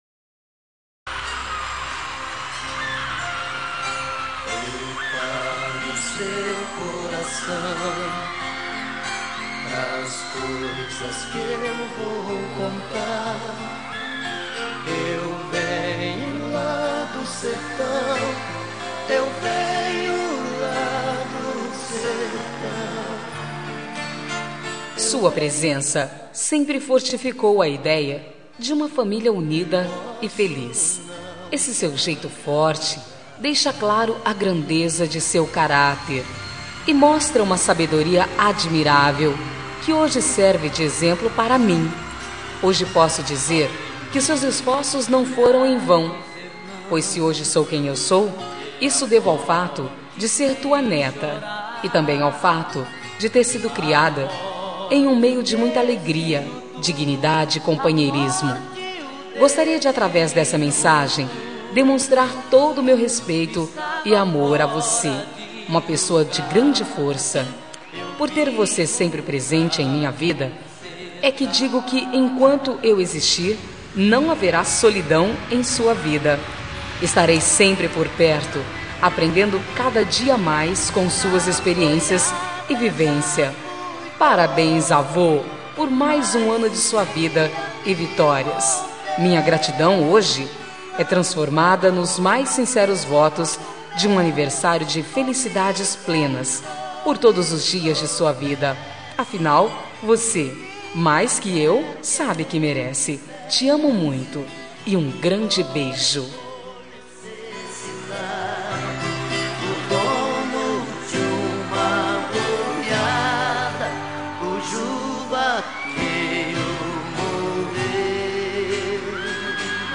Aniversário de Avô – Voz Feminina – Cód: 2095